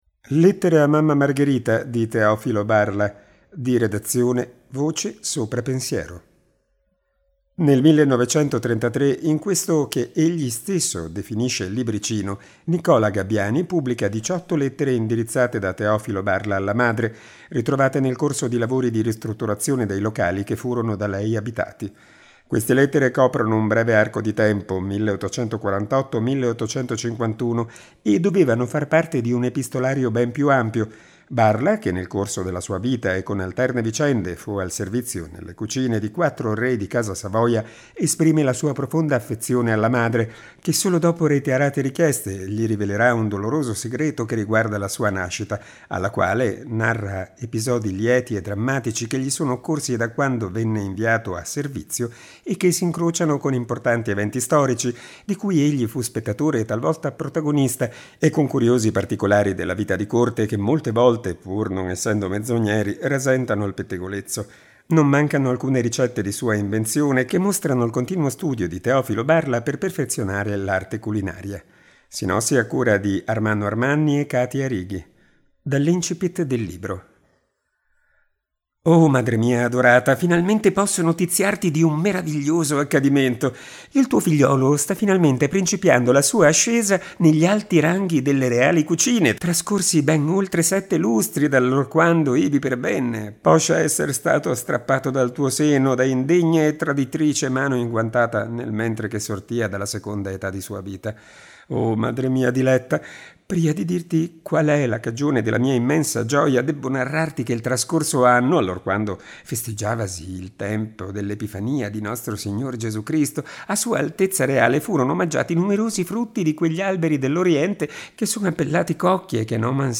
Dall’incipit del libro: